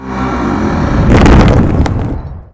tree_falling.wav